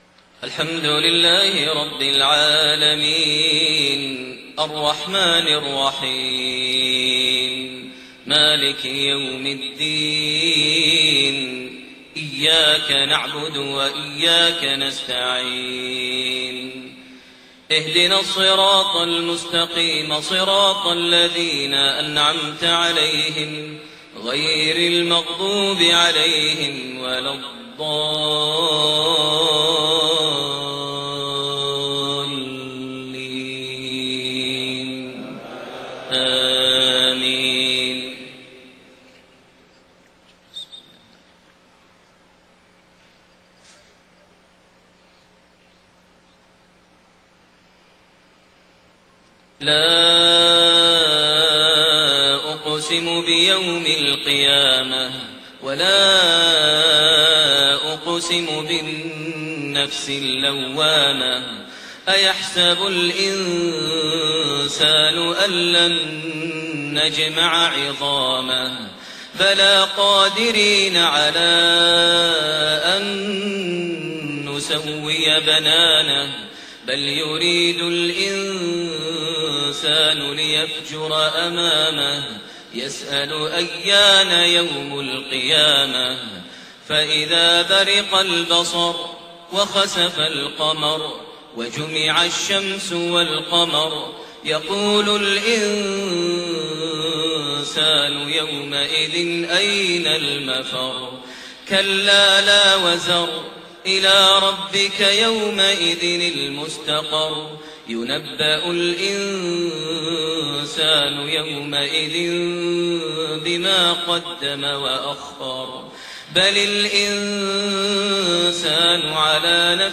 maghrib prayer from Surah Al-Qiyaama > 1430 H > Prayers - Maher Almuaiqly Recitations